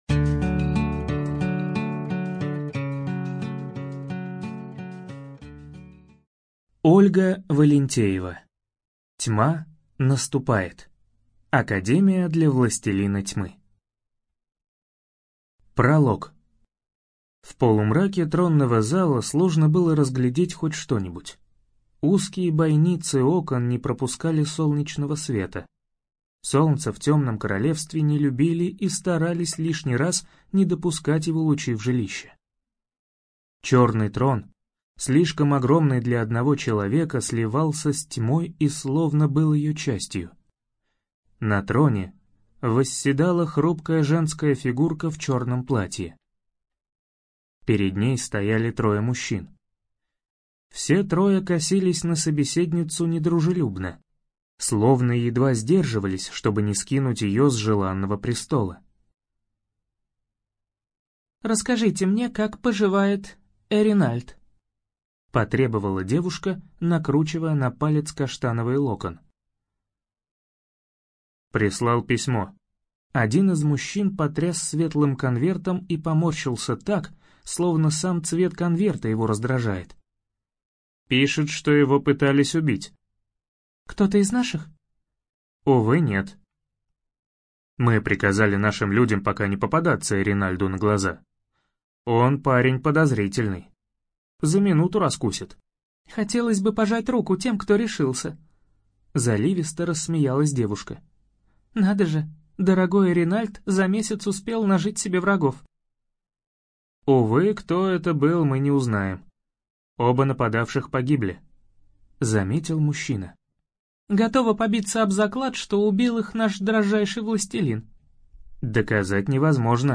ЖанрФэнтези